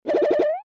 boing.mp3